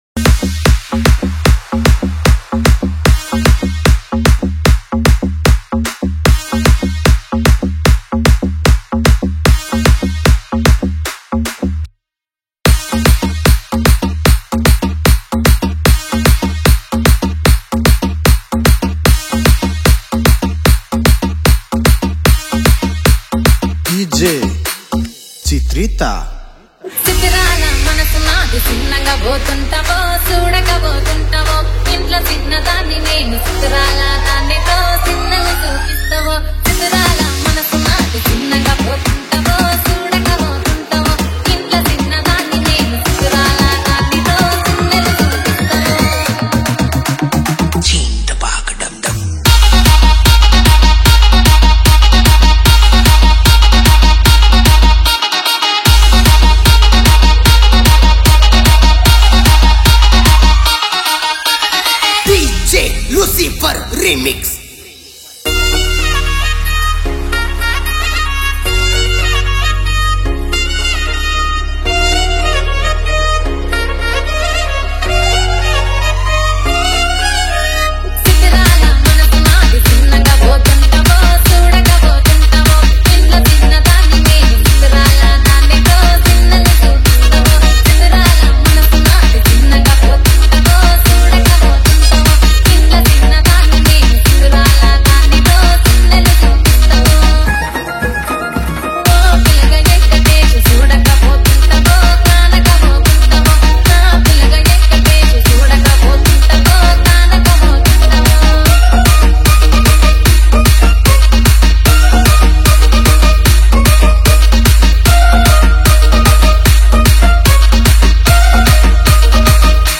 Telug Dj Collection 2024 Songs Download